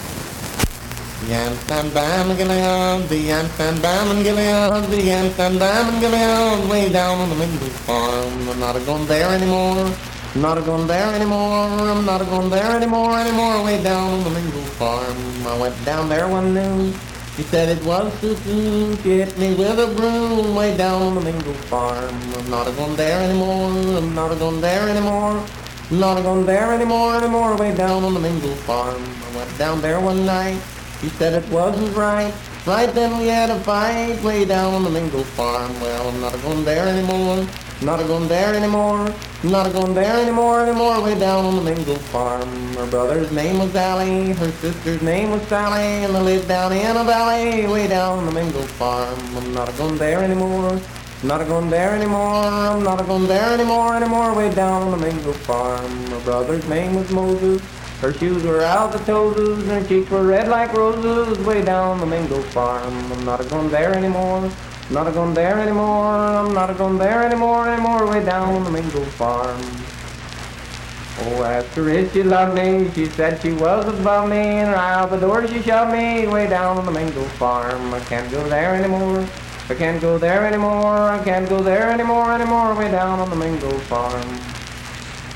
Unaccompanied vocal performance
Dance, Game, and Party Songs
Voice (sung)
Spencer (W. Va.), Roane County (W. Va.)